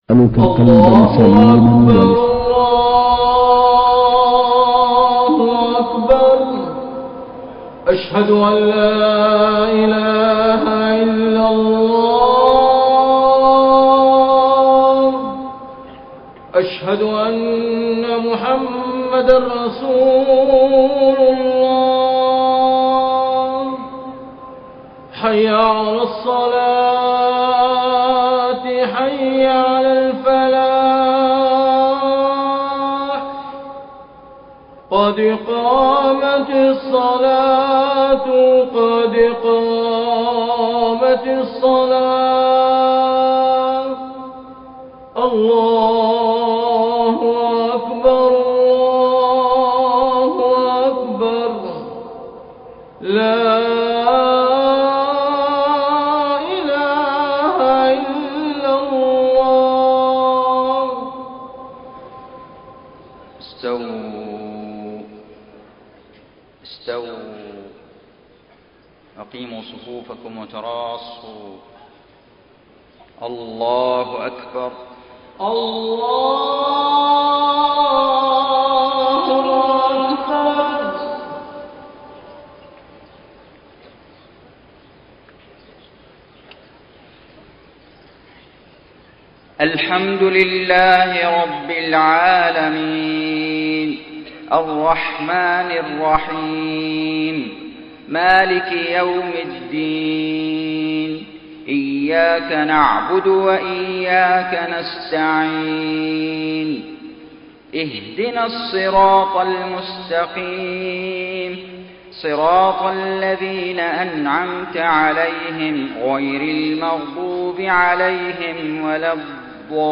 صلاة الفجر 19 ذو القعدة 1432هـ من سورة طه 99-127 > 1432 🕋 > الفروض - تلاوات الحرمين